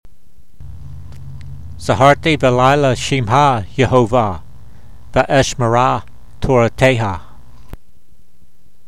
v55_voice.mp3